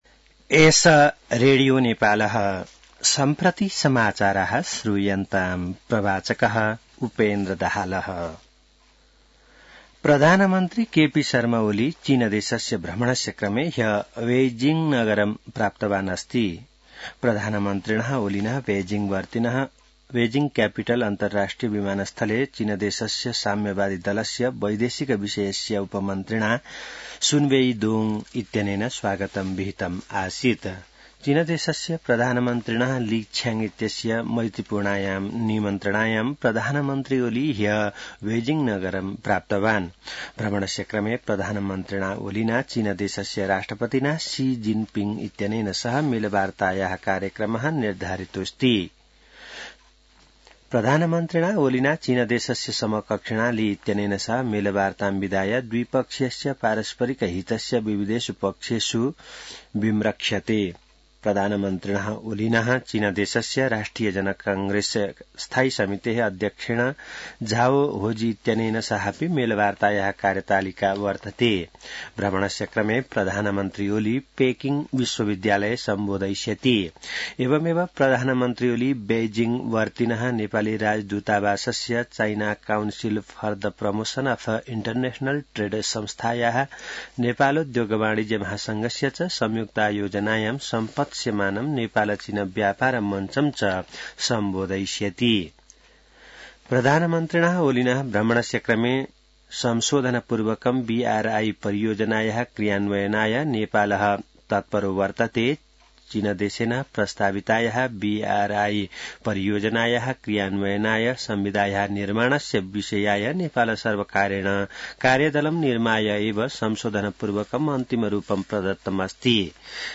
An online outlet of Nepal's national radio broadcaster
संस्कृत समाचार : १९ मंसिर , २०८१